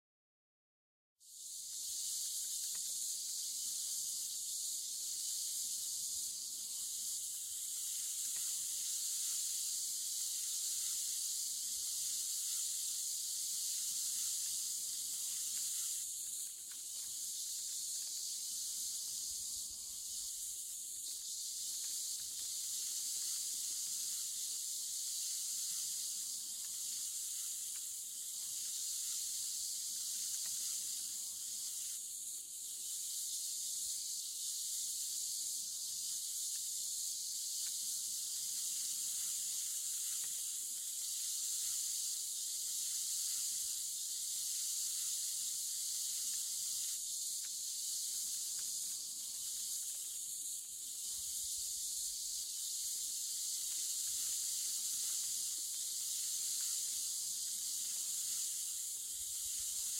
На этой странице собраны уникальные звуки песочных часов — от мягкого пересыпания песка до четких щелчков при перевороте.
Эффект песочных часов за 60 секунд